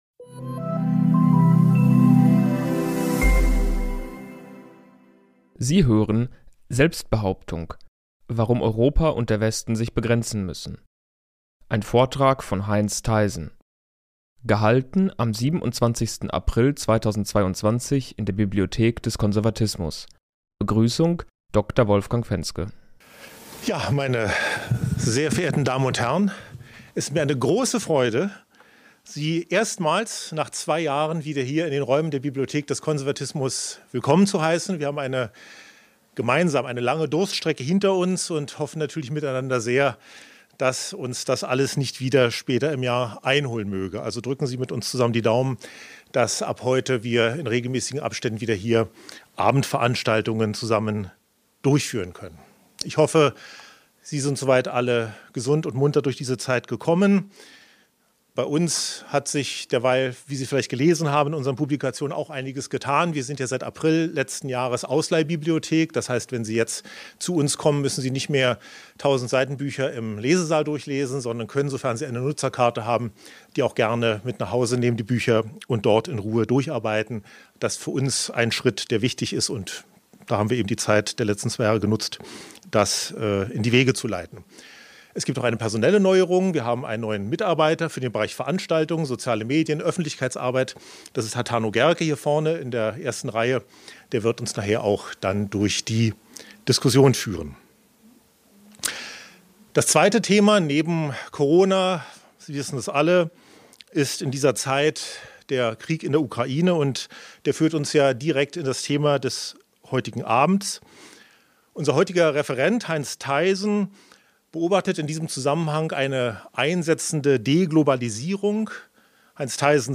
Am 27. April 2022 konnte die Bibliothek des Konservatismus nach einer nahezu zweijährigen Zwangspause endlich wieder den Veranstaltungsbetrieb aufnehmen.